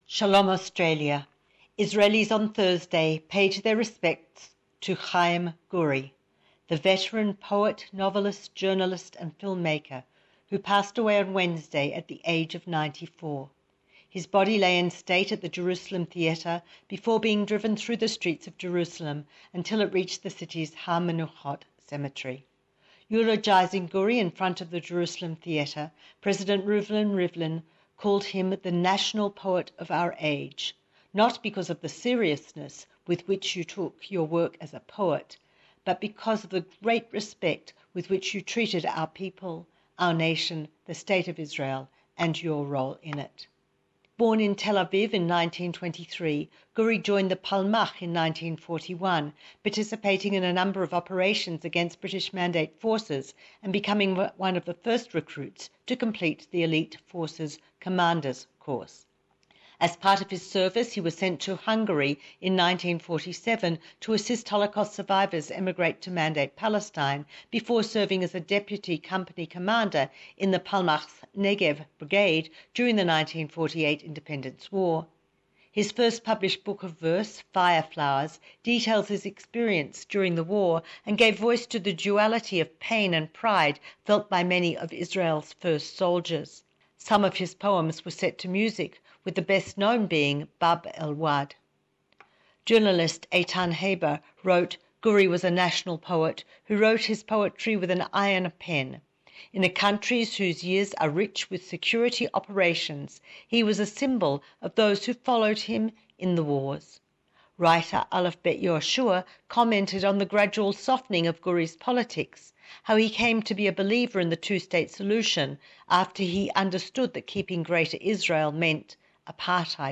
Don't miss our weekly informative and unique Jerusalem report. Find out about the death of Israeli National Poet Chim Gouri and much more...